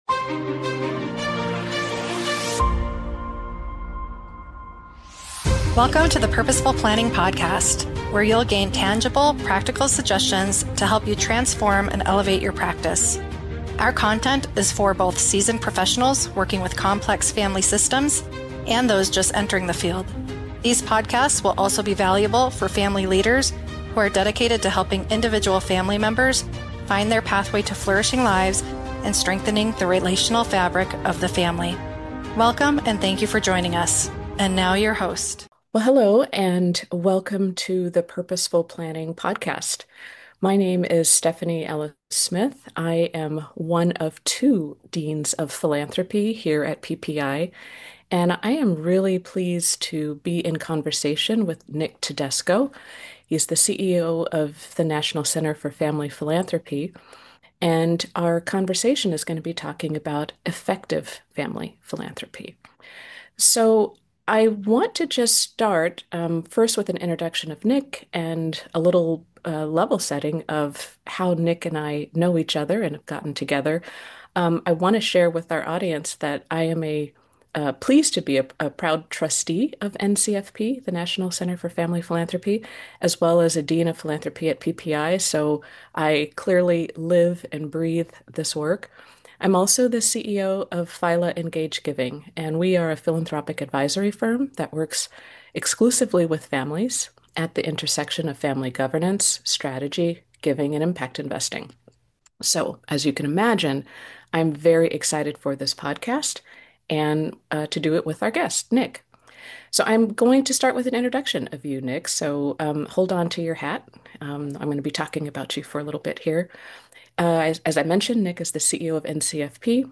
Together they explore how clarity of purpose, equity, and family alignment can make giving both more joyful and more purposeful. Tune in for a grounded, hopeful discussion about how generosity can meet this moment with intention and impact.